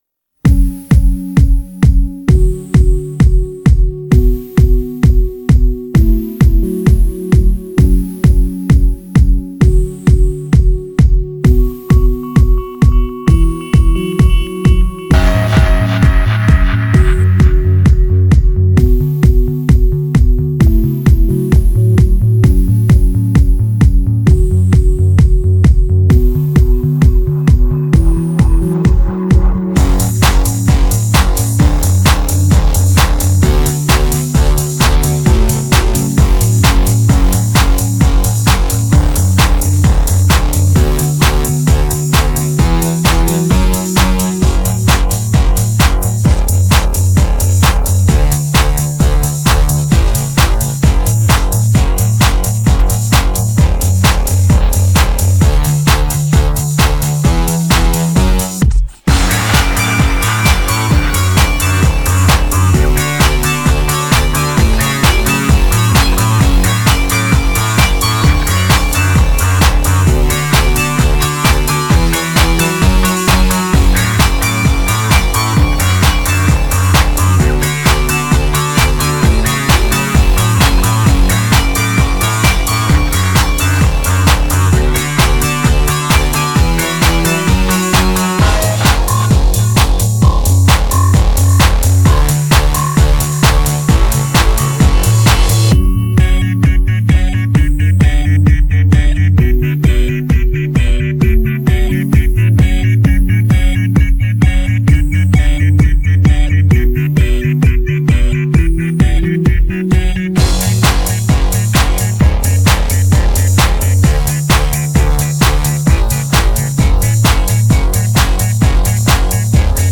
Российский поп